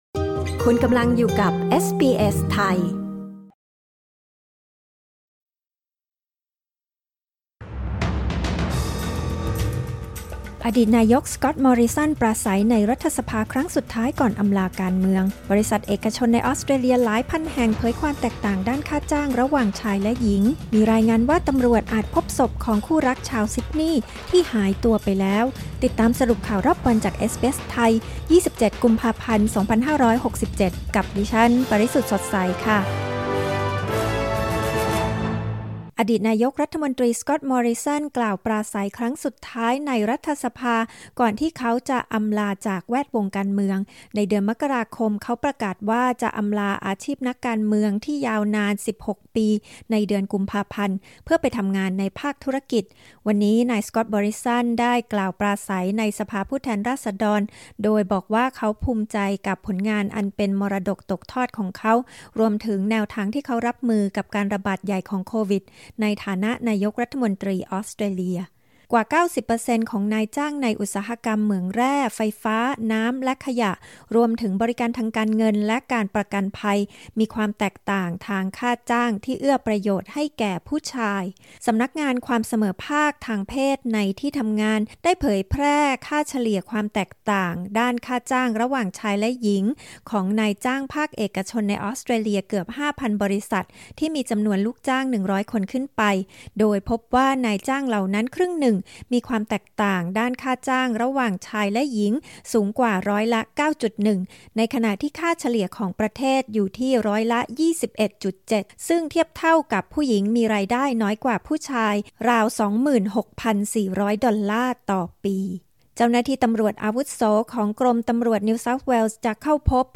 คลิก ▶ ด้านบนเพื่อฟังรายงานข่าว